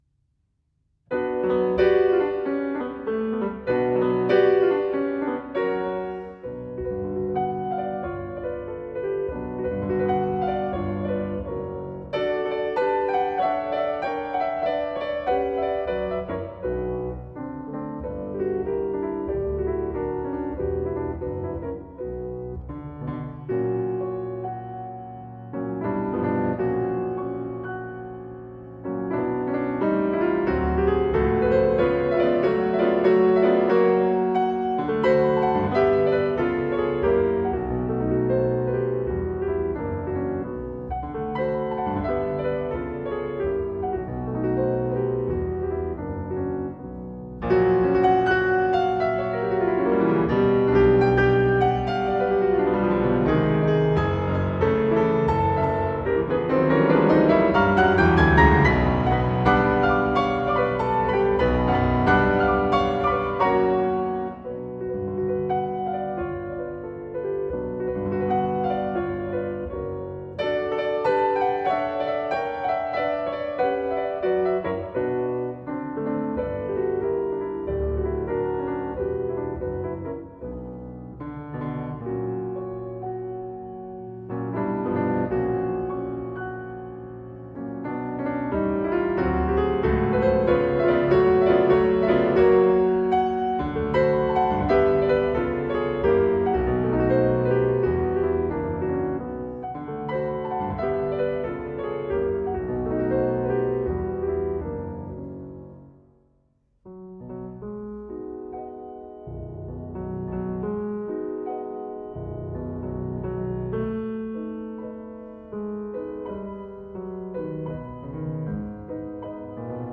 feminine Norwegian dance
piano